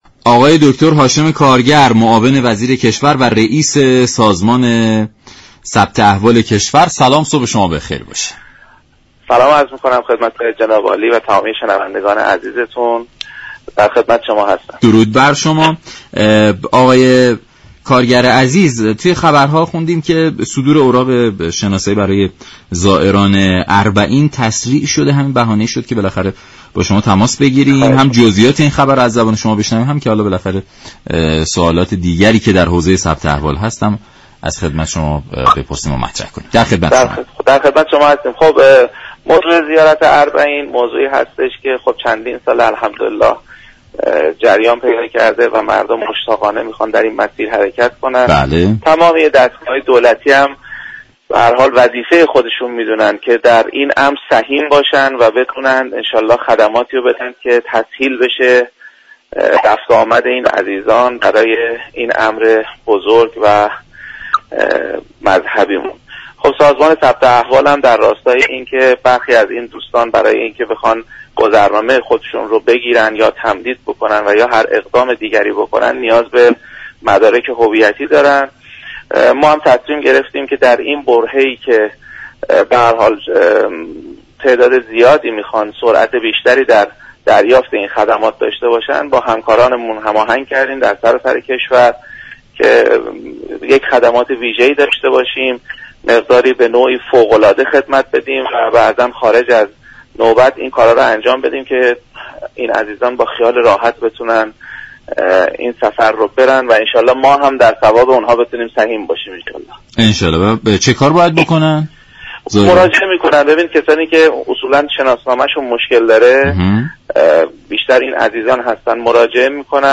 به گزارش شبكه رادیویی ایران، «هاشم كارگر» معاون وزیر كشور و رییس سازمان ثبت احوال، در برنامه «سلام صبح بخیر» رادیو ایران درباره تسریع صدور اوراق شناسایی برای زائران اربعین گفت: راهپیمایی اربعین چند سالی است كه با استقبال زیاد مردم روبرو شده دولت نیز در طول این سال ها، همگام با زائران، تمهیداتی را برای هر چه باشكوه برگزار شدن این مراسم انجام داده است.